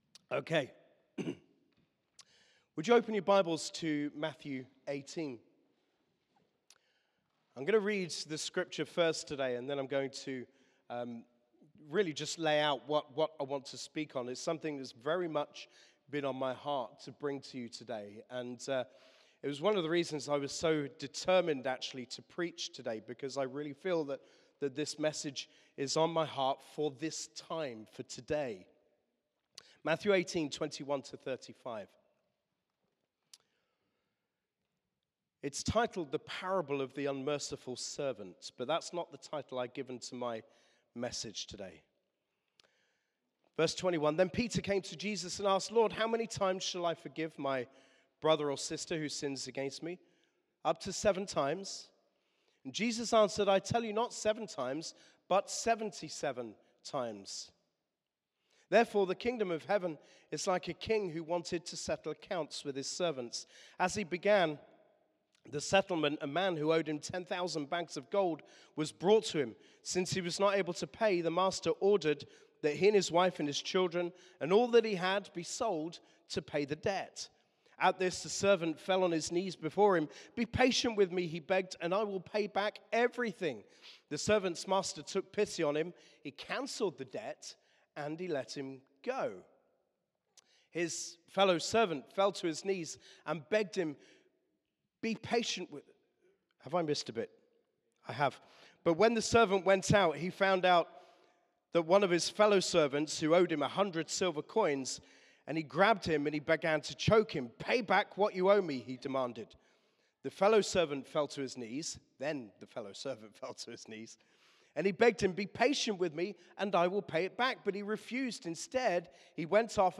Sermon - Forgiveness: Matthew 18